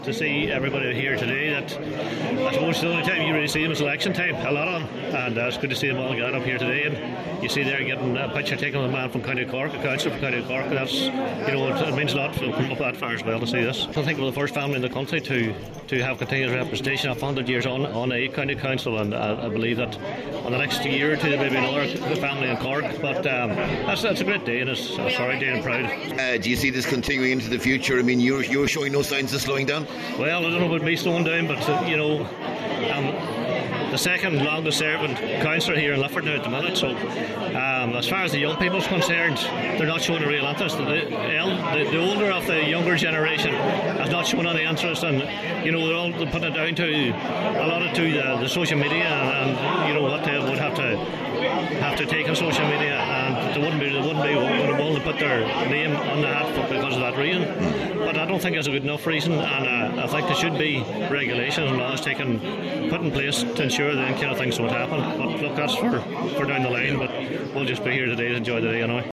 Cllr Liam Blaney says it’s a proud day for the family: